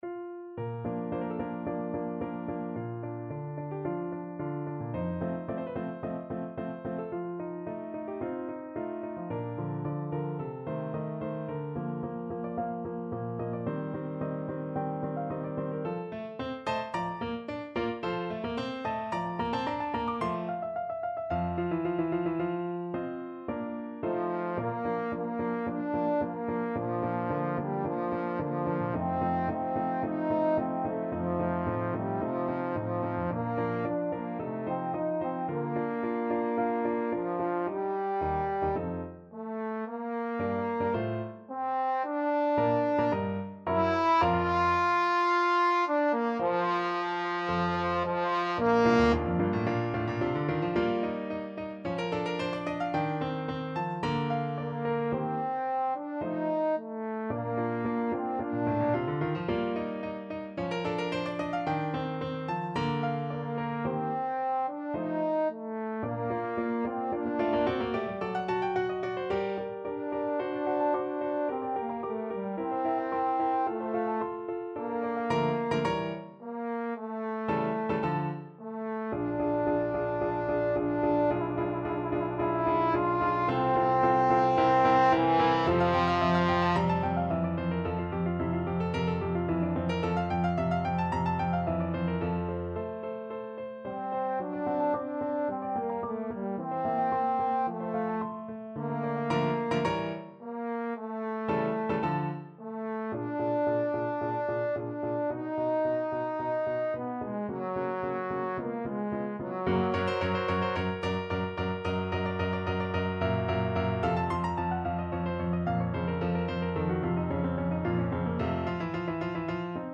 4/4 (View more 4/4 Music)
Andante =110
Classical (View more Classical Trombone Music)